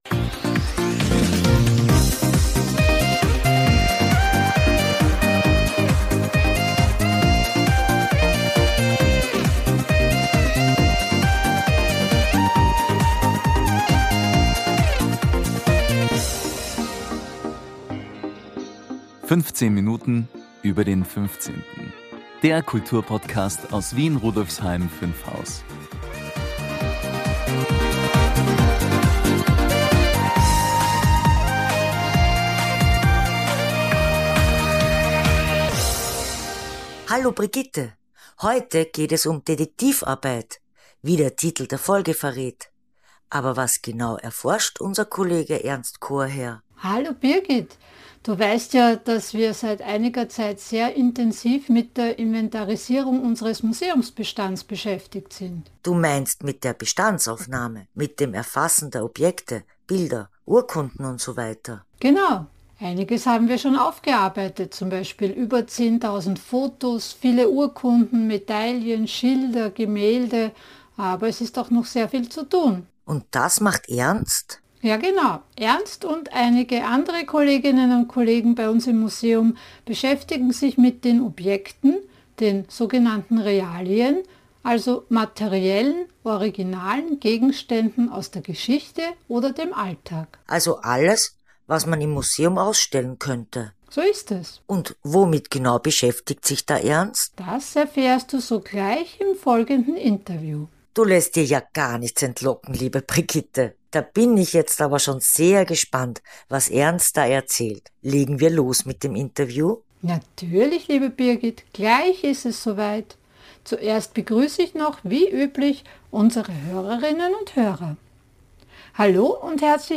Außerdem geben wir Ihnen einen Überblick über kommende Veranstaltungen, einen unerwarteten Gastauftritt von Paul, dem KI-Co-Moderator, und einen geheimnisvollen Ausblick auf die nächste Folge, in der ein besonderes Museumsjubiläum im Mittelpunkt steht.